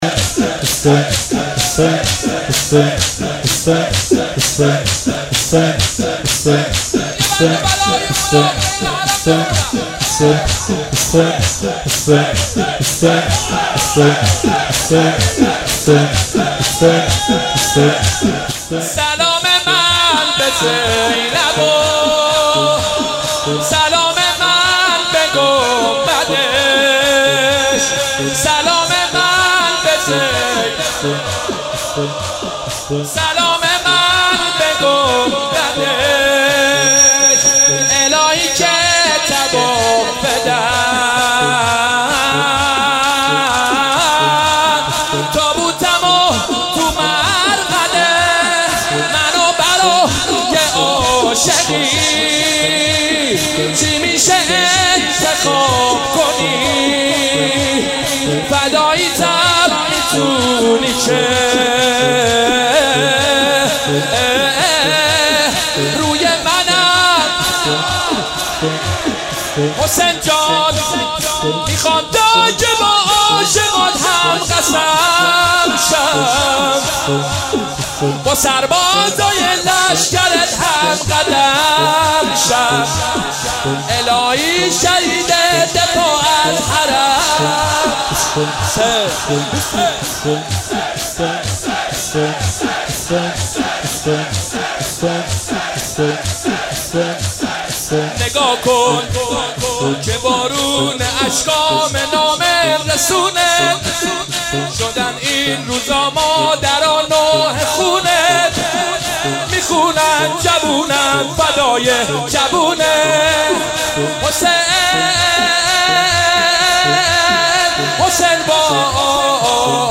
مداح
مناسبت : شب نوزدهم رمضان - شب قدر اول
قالب : شور